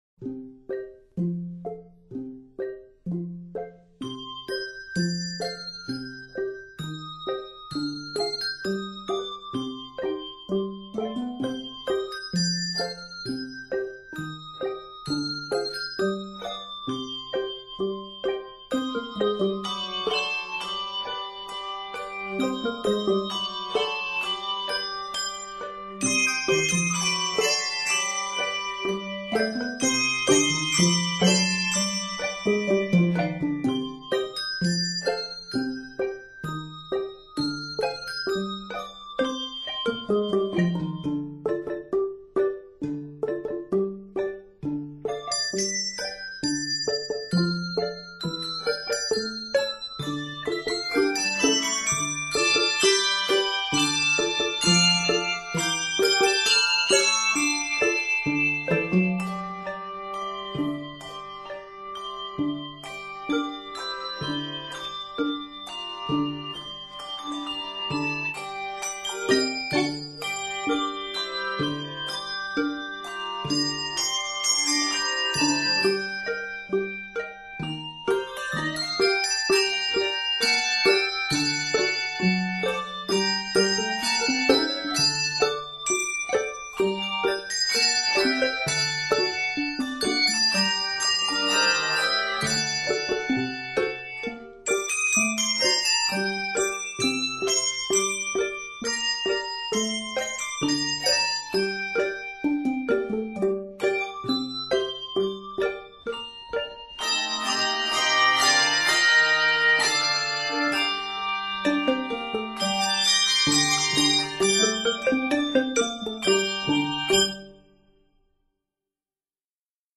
Traditional English Carol Arranger